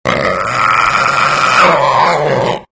Рычание пантеры в темноте